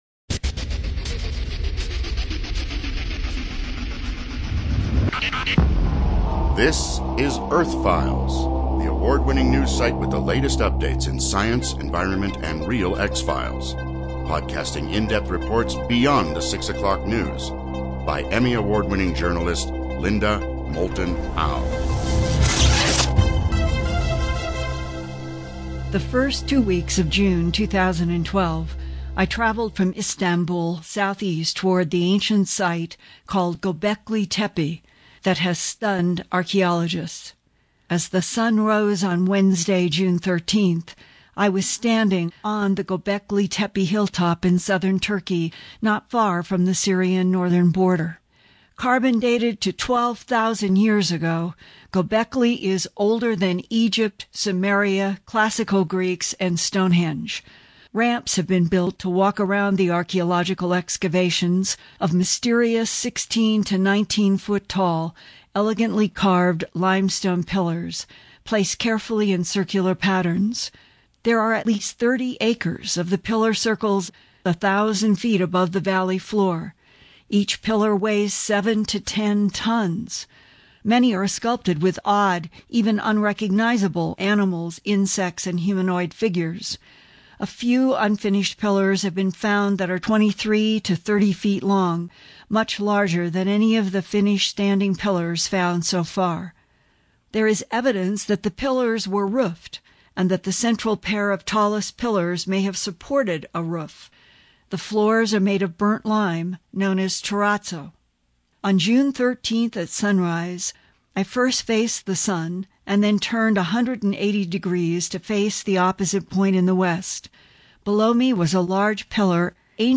Earthfiles Reporter and Editor Linda Moulton Howe interviewed Robert M. Schoch, Ph.D., Geologist, Boston University, inside the Gobekli Tepe, Turkey, excavation site on June 13, 2012. The T-shaped pillars have an anthropomorphic identity.